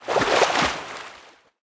snapshot / assets / minecraft / sounds / liquid / swim13.ogg
swim13.ogg